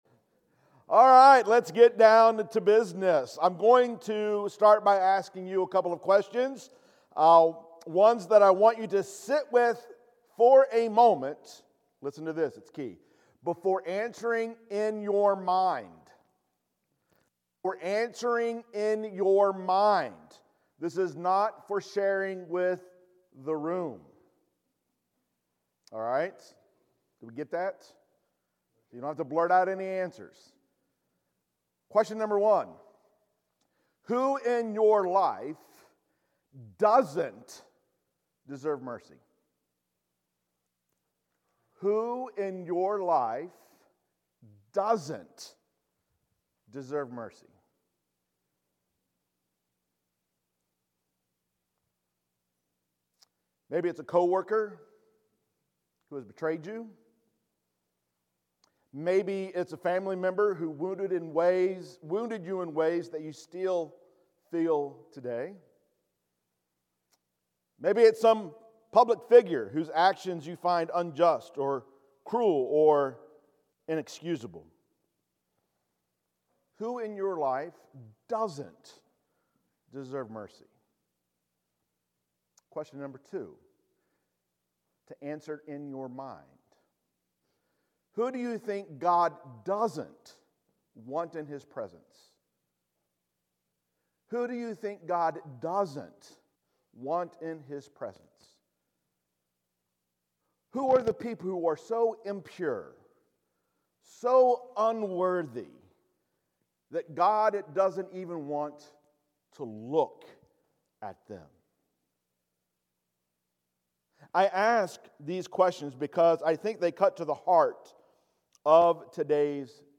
Sermons | Wheeler Road Church of Christ